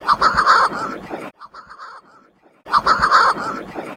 PARDELA BOREAL
NOMBRE CIENTÍFICO Puffinus puffinus puffinus